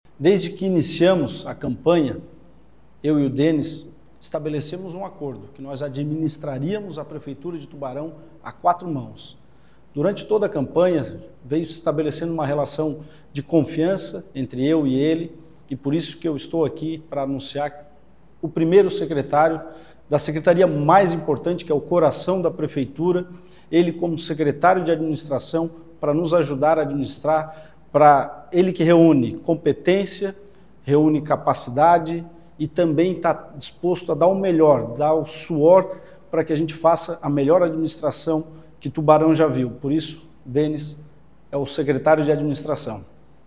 ÁUDIO - ANÚNCIO DENIS - FALA SORATTO
ÁUDIO-ANÚNCIO-DENIS-FALA-SORATTO.mp3